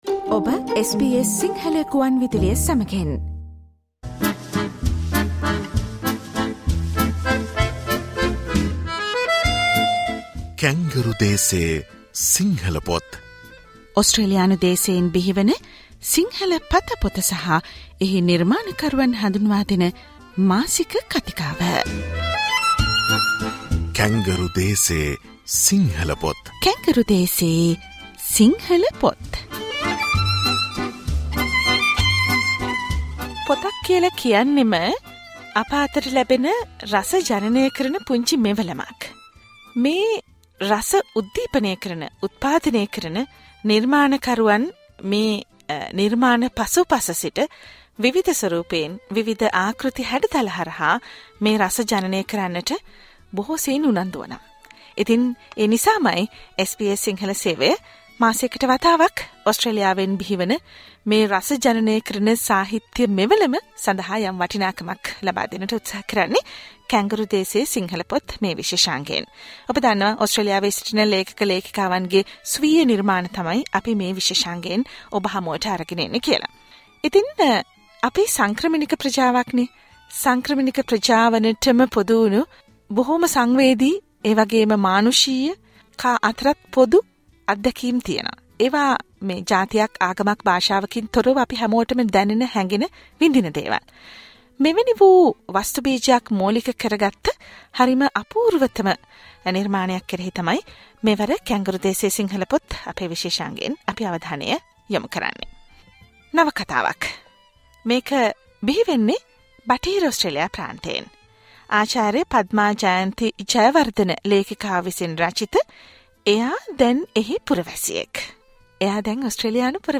SBS Sinhala interview on a novel